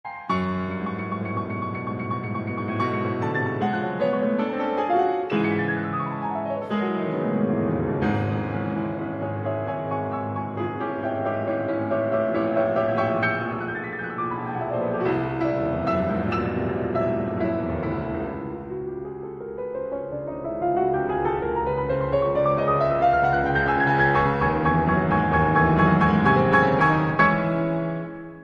تُسمع نوتات متفرقه تصعد لأعلى المدى الصوتي كطير ضعيف يحاول الهروب من عاصفه حتميه، يفشل من الهروب، فيسقط في دوامة العاصفه مترنحاً يميناً و يساراً، فيتوه ويفقد اتجاهات المكان كما فُقدت التوناليتي (فُقد الأساس الهارموني الذي يقود النغمات ويكوّن تأثيرها)